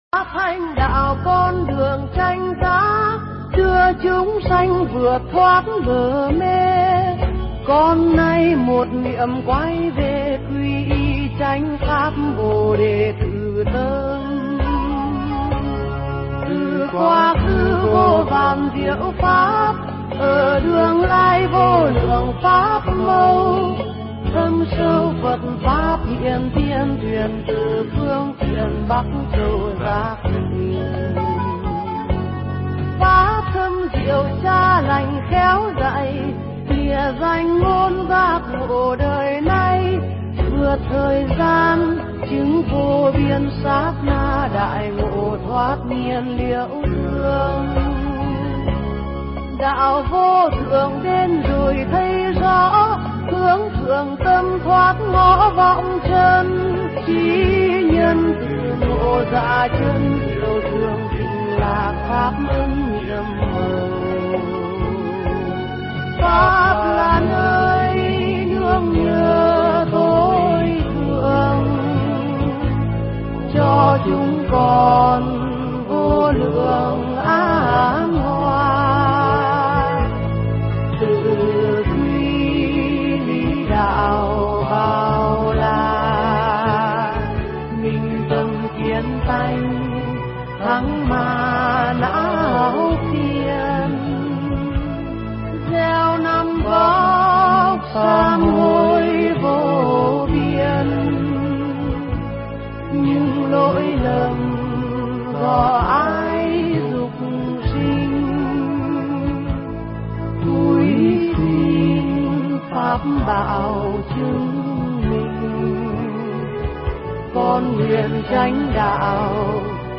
Nghe Mp3 thuyết pháp Suy Ngẫm Sự Đổi Thay
Nghe mp3 pháp thoại Suy Ngẫm Sự Đổi Thay